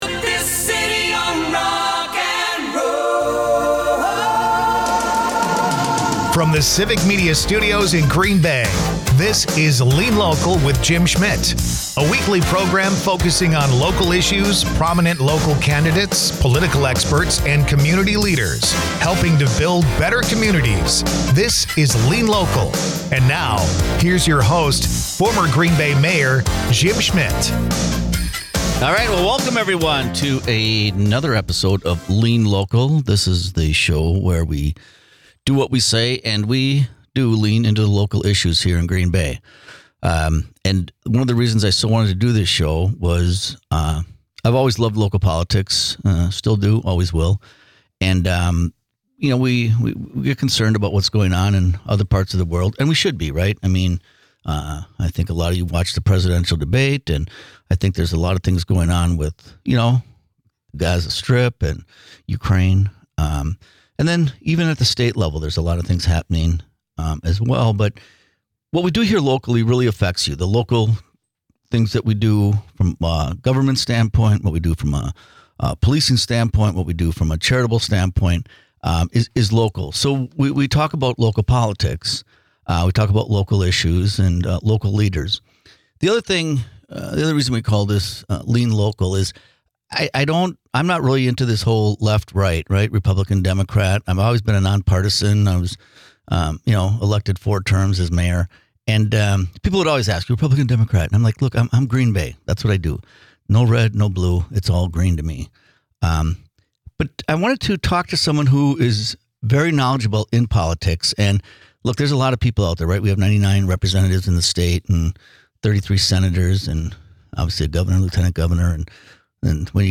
Dive into the heart of community issues with 'Lean Local,' hosted by former Green Bay Mayor Jim Schmitt. This refreshing political and issues program bypasses traditional left-versus-right rhetoric. Instead, it 'leans local' with insightful discussions and grassroots solutions, focusing on what truly matters in our neighborhoods and communities. 4th Of July Special June 30, 2024 Guest: John Macco Former Green Bay Mayor Jim Schmitt is joined by John Macco, politician from Madison who isn't running anymore.